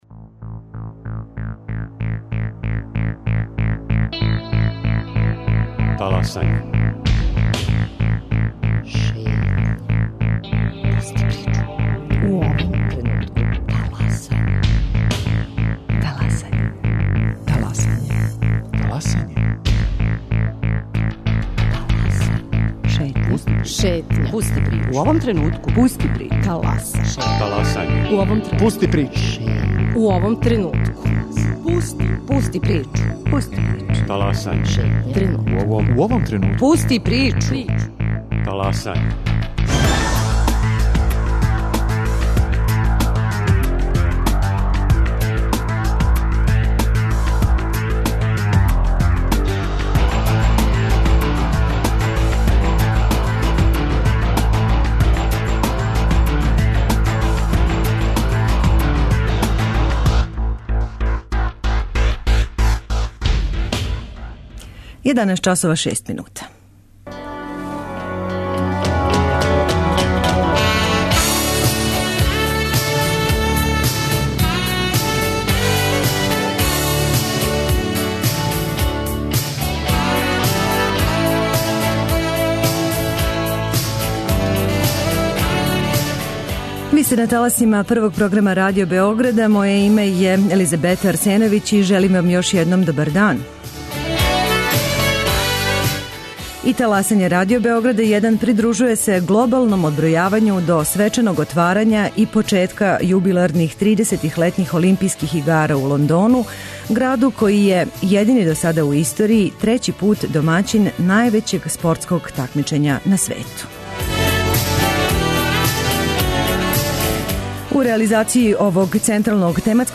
Магазин на Првом: Таласање - Пусти причу